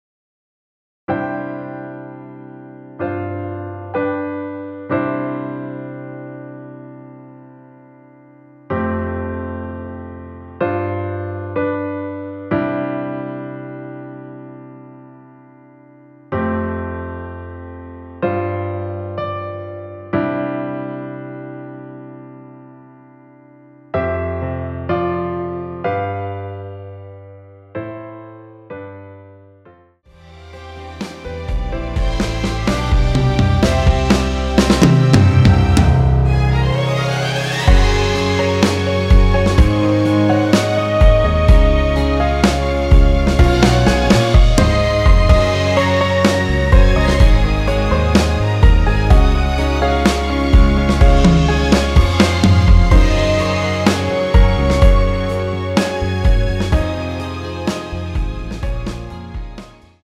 전주 없이 시작하는 곡이라 전주 만들어 놓았습니다.(미리듣기 확인)
원키에서(+3)올린 MR입니다.
앞부분30초, 뒷부분30초씩 편집해서 올려 드리고 있습니다.
중간에 음이 끈어지고 다시 나오는 이유는